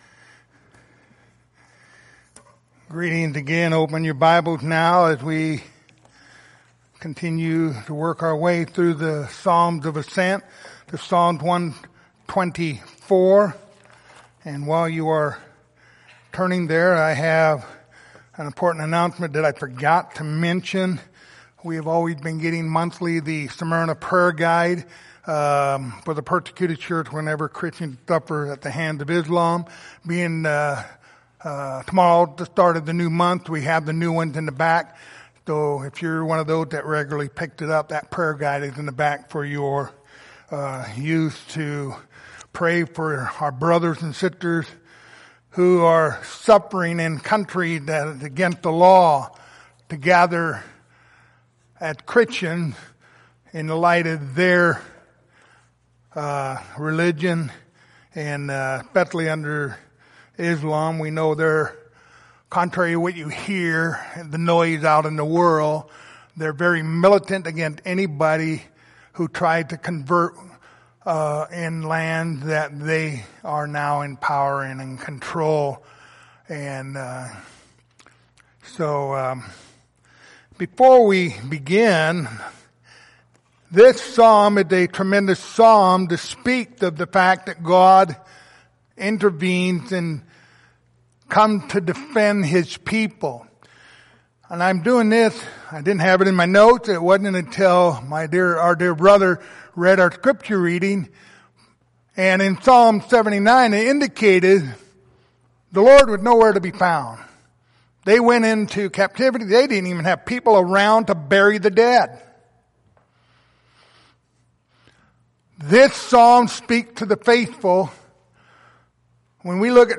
Passage: Psalm 124:1-8 Service Type: Sunday Morning